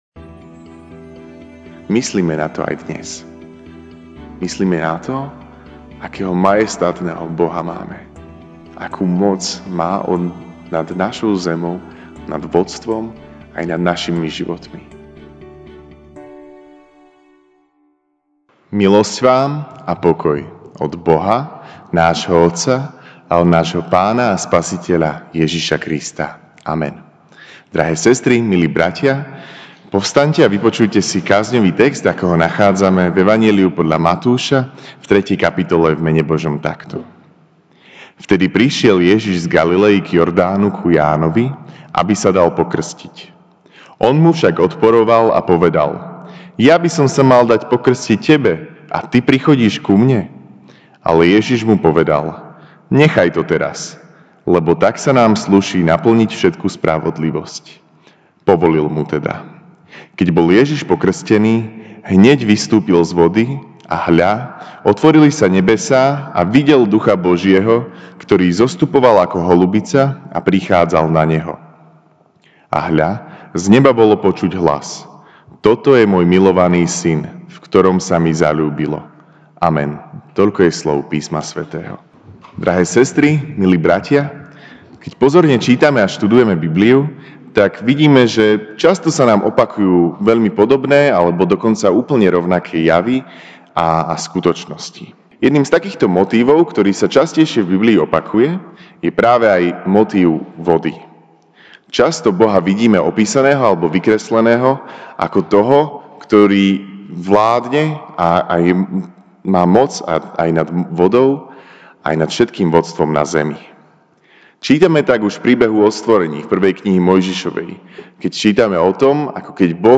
jan 13, 2019 O vode v Biblii MP3 SUBSCRIBE on iTunes(Podcast) Notes Sermons in this Series Večerná kázeň: O vode v Biblii (Mt 3, 13-17) Vtedy prišiel Ježiš z Galiley k Jordánu ku Jánovi, aby sa mu dal pokrstiť.